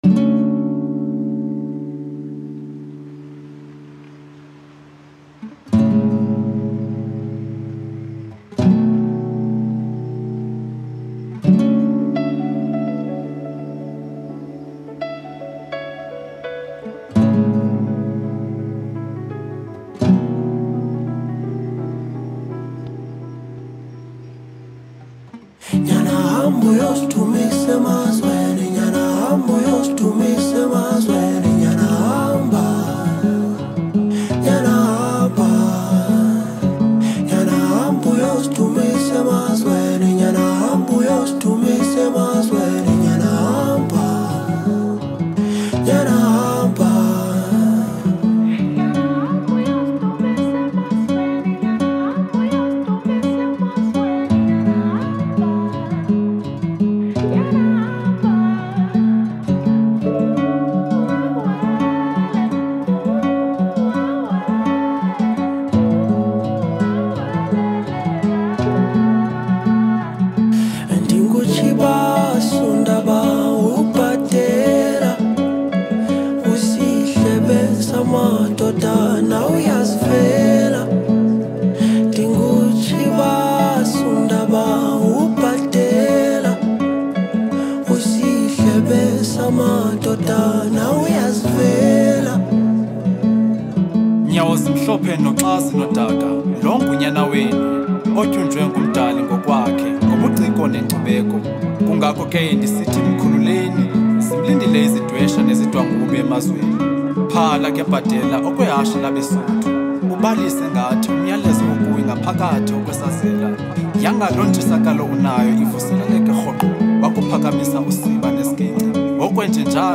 Home » Amapiano
captivating and harmonious tune